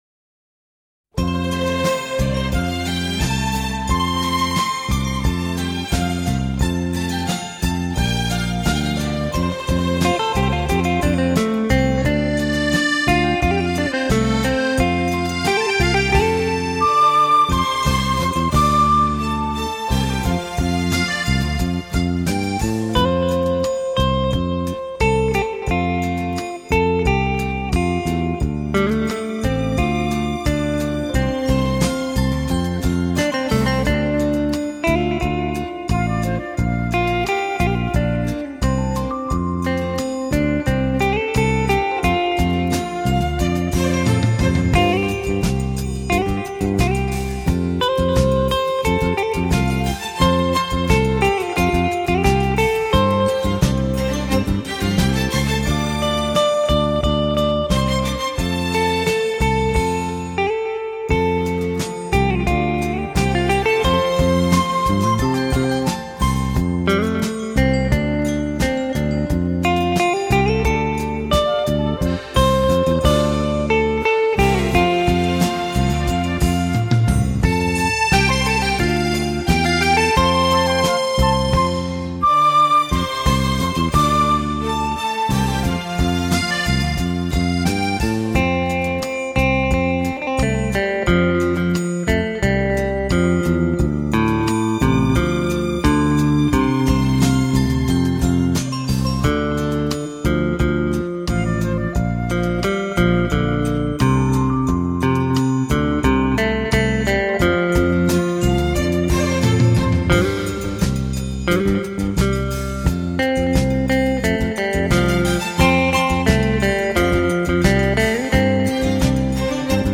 在整张精选集录音中，吉他的音色通透自然、清晰明亮而又温暖饱满，将这些宛如星空中最闪亮的星光一般的经典演绎得更加不朽！